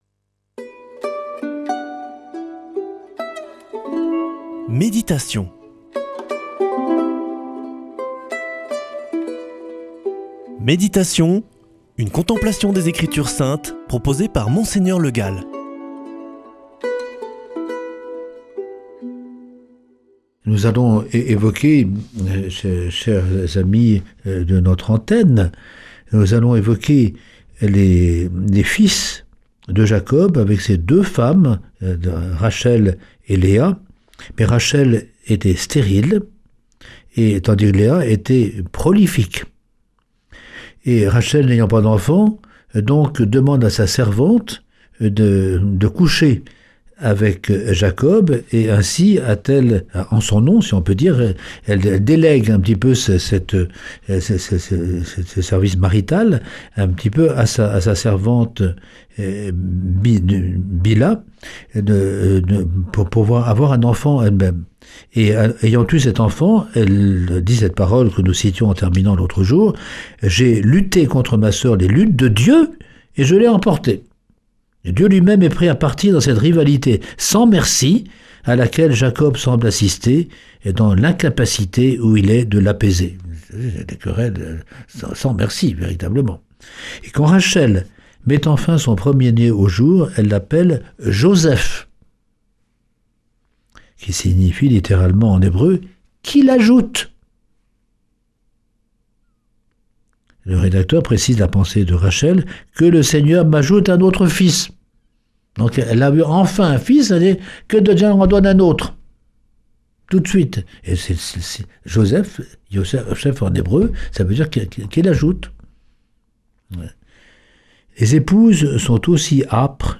Méditation avec Mgr Le Gall
[ Rediffusion ] Les enfants de Jacob